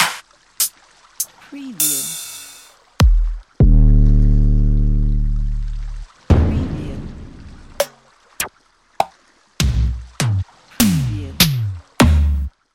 سمپل تک ضربی (وان شات) | 3000 سمپل وان شات
سمپل تک ضربی (وان شات) بیش از 3 هزار سمپل پرکاشن و درامز به صورت وان شات (تک ضربی) دسته بندی شده و با کیفیت بالا